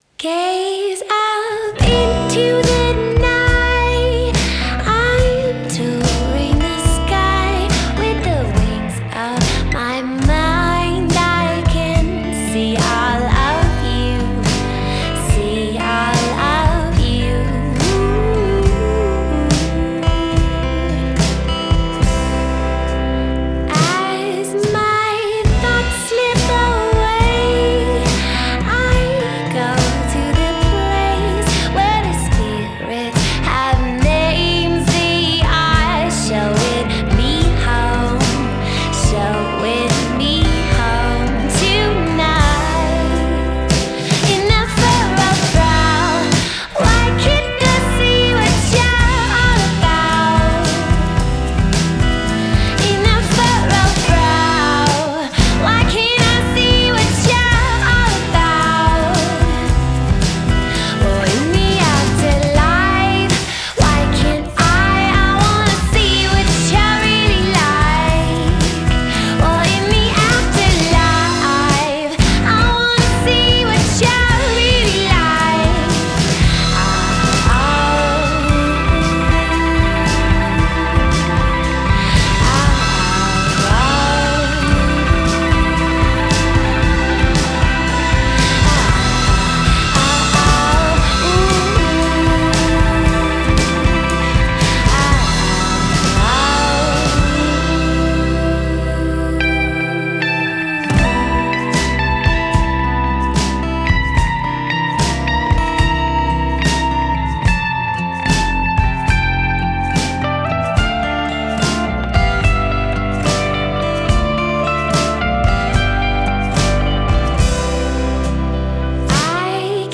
Genre: Indie Rock / Folk